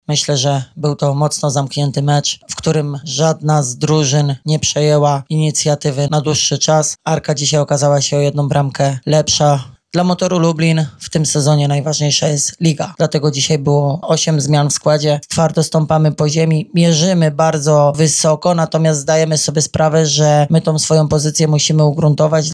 ARKA-GDYNIA-MOTOR-LUBLIN-10.-TRENERZY-PO-MECZU.mp3